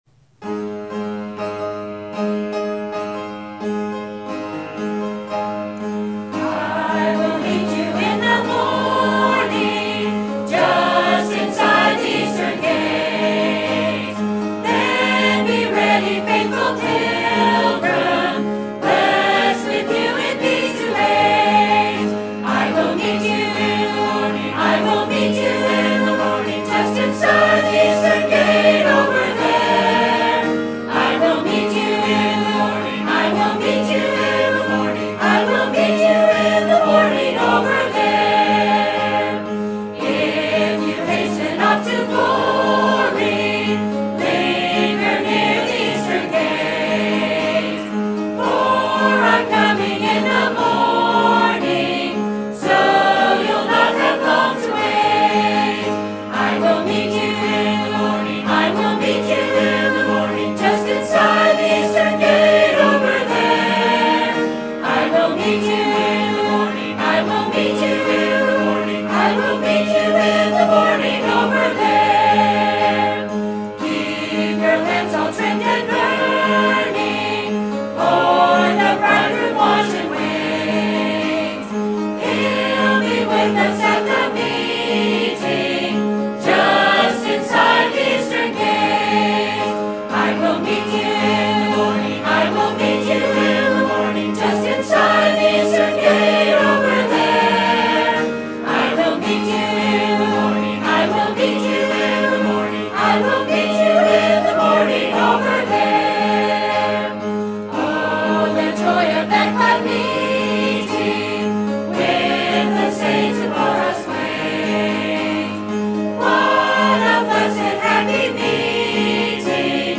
Sermon Topic: General Sermon Type: Service Sermon Audio: Sermon download: Download (30.26 MB) Sermon Tags: Genesis Repentance God Obey